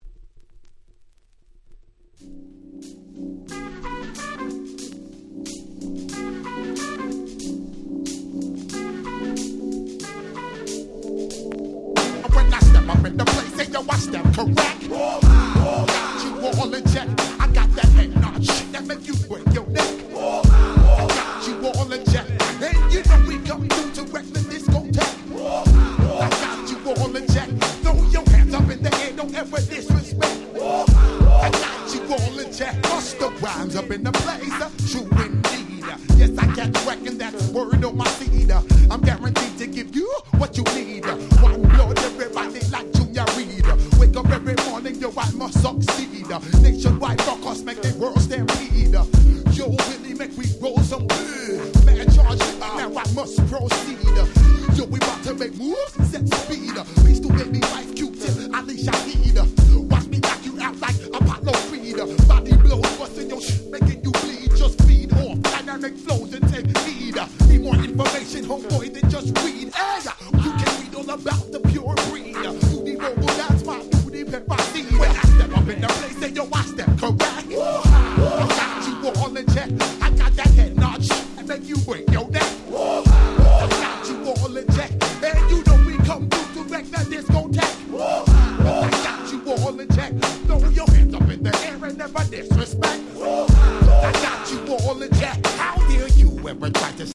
96’ Super Hit Hip Hop !!